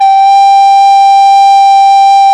SOP REC-G4.wav